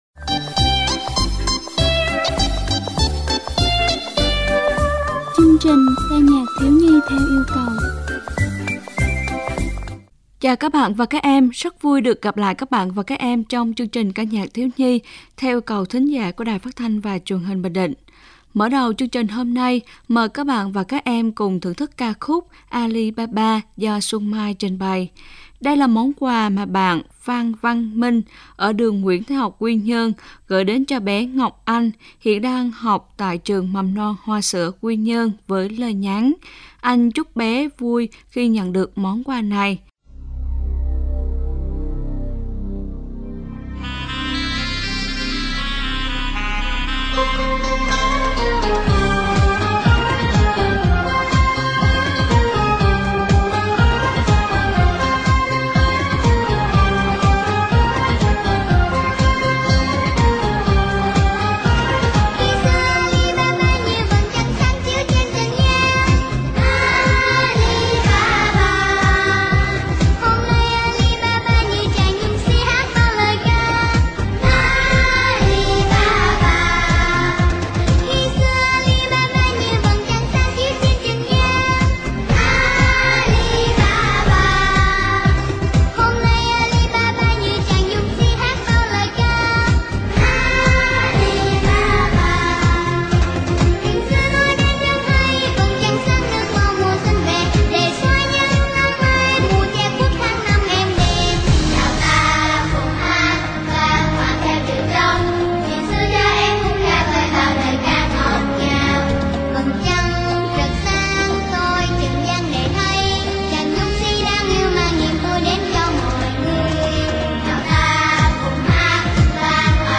Đài Phát thanh và Truyền hình Bình Định